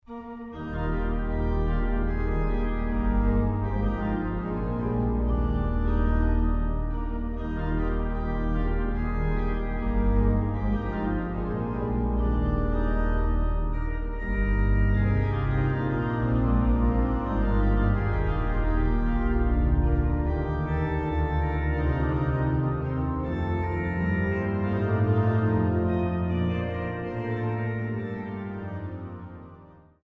Kaps-Orgel in Mariä Himmelfahrt zu Dachau